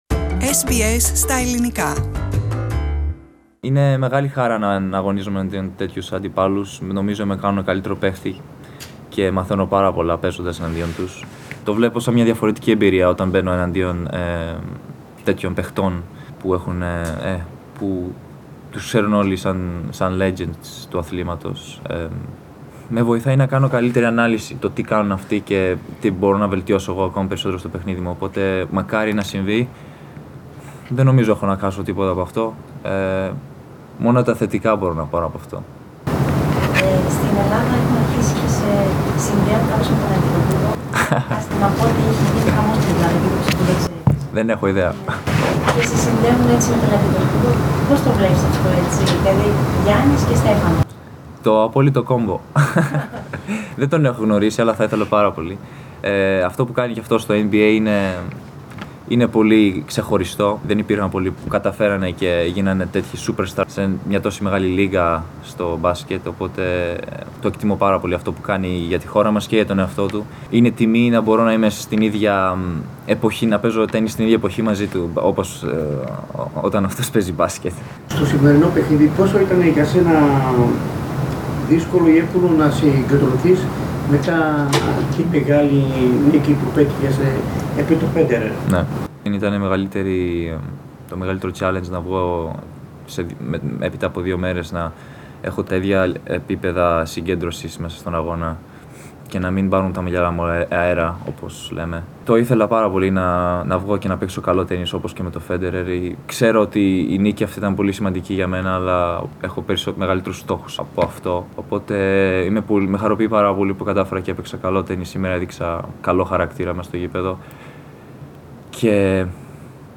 Έτοιμος να αντιμετωπίσει όποιον αθλητή βρεθεί στην πορεία του, δήλωσε ο Στέφανος Τσιτσιπάς στη συνέντευξη Τύπου που παραχώρησε (στα Ελληνικά) μετά την πρόκρισή του στον ημιτελικό της Πέμπτης.